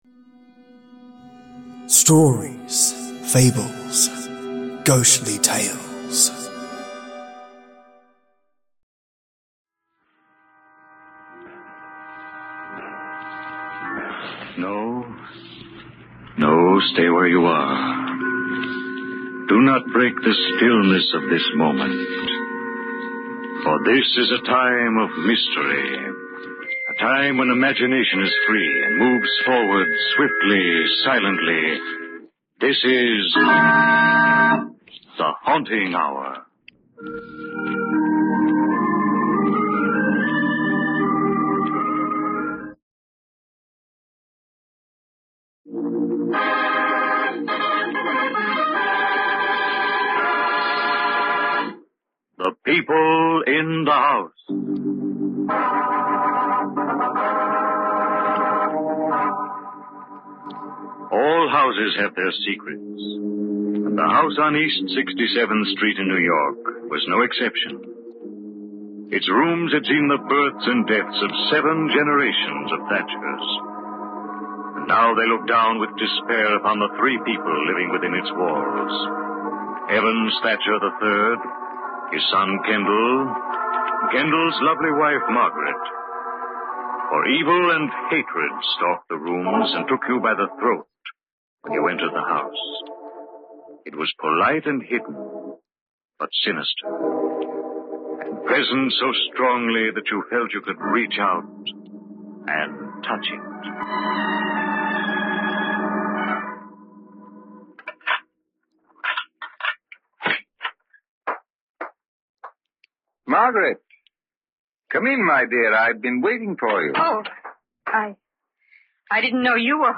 Two awesome tales straight from HAUNTING HOUR Golden Radio, completely remastered and their audio gated so that PESKY noise can go take a jog.